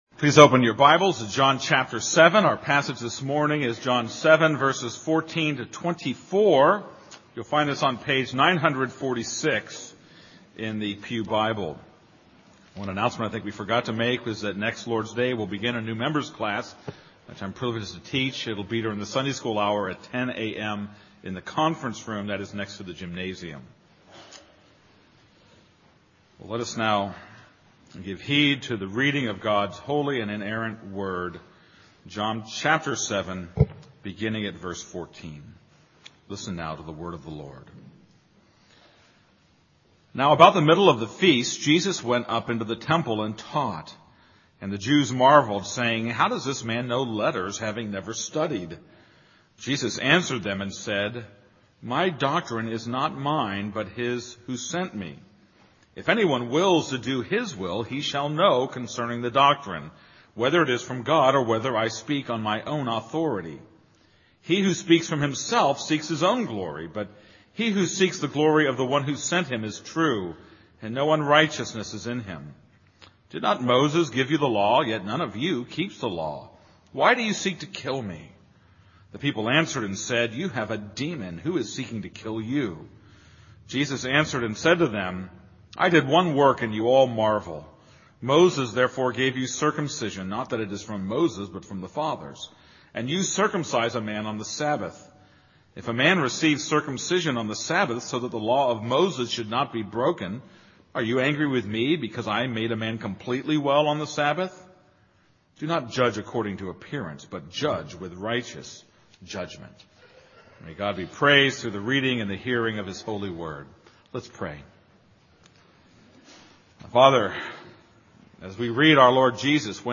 This is a sermon on John 7:14-24.